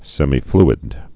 (sĕmē-flĭd, sĕmī-)